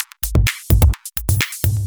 Index of /VEE/VEE Electro Loops 128 BPM
VEE Electro Loop 432.wav